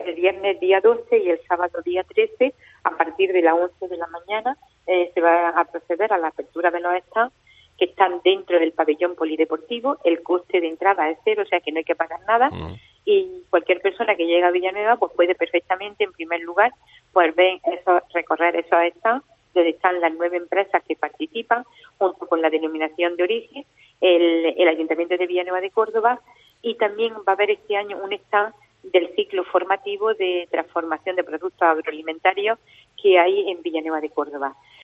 Escucha a Lola Sánchez, alcaldesa de Villanueva de Córdoba sobre la Feria del Jamón